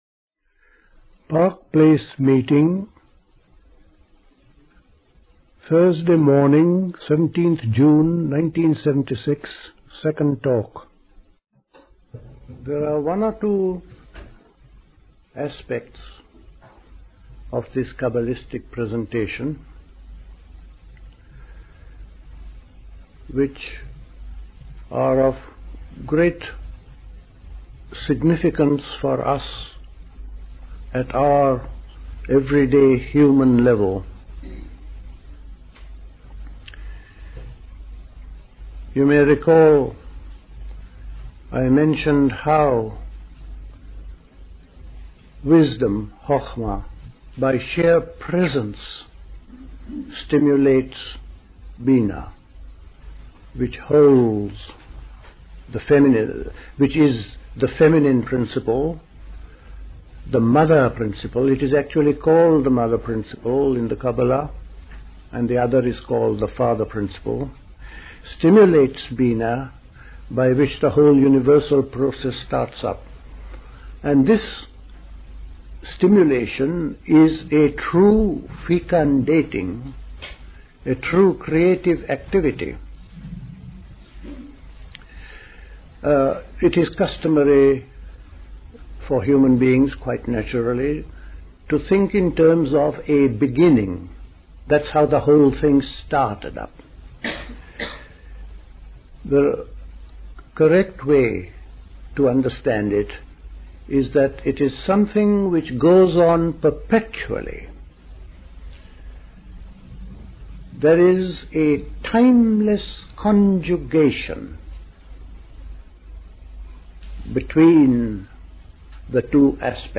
Recorded at the 1976 Park Place Summer School.